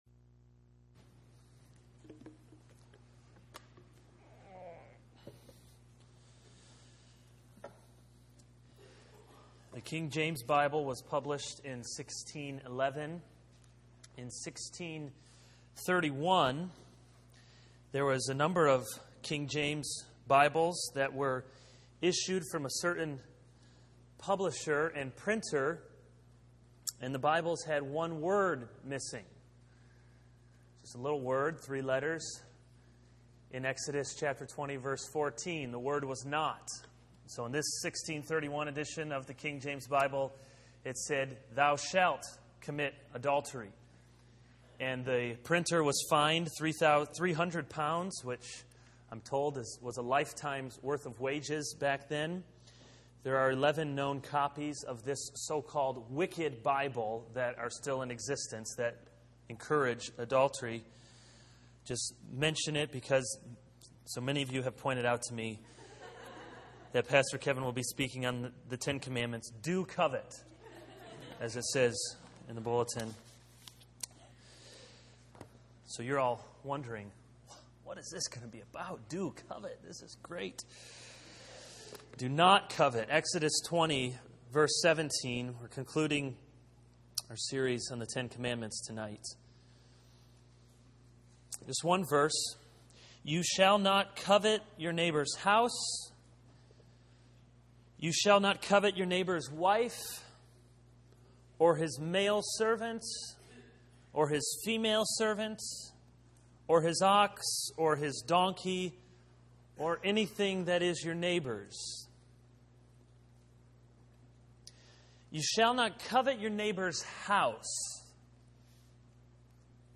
This is a sermon on Exodus 20:1-17 - Do not covet.